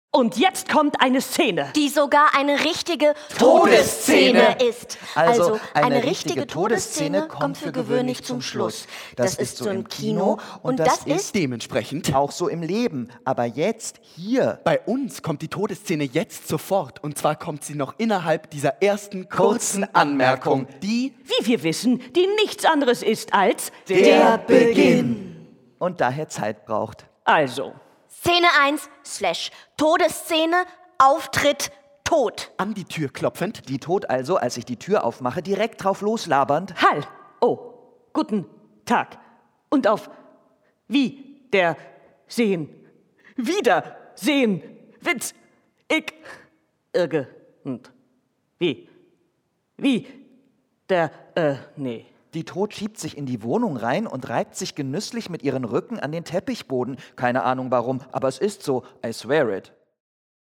Audiomitschnitt
Schon der Einlass ins Theater Drachengasse beginnt als große WG-Party, auf der ausgelassen getanzt wird.